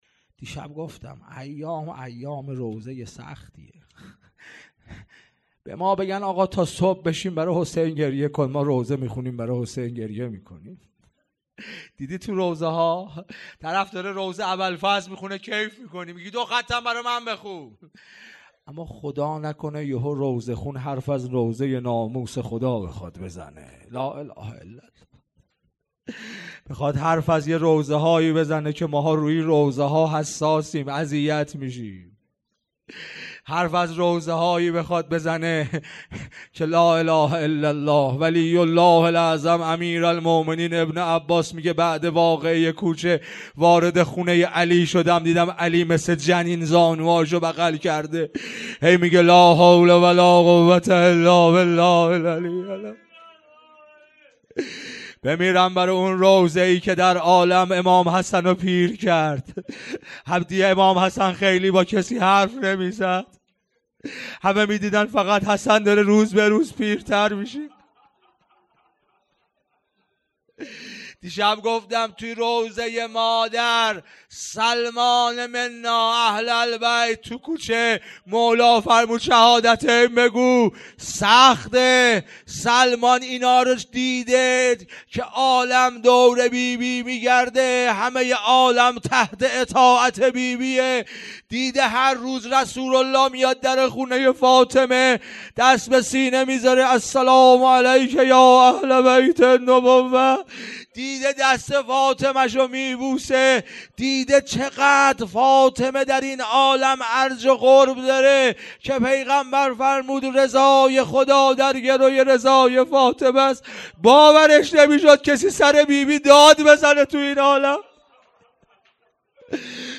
روضه
شب دوم مراسم شهادت حضرت محسن ابن علی علیه السلام ۱۴۰۲
سخنرانی